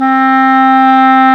WND  CLAR 06.wav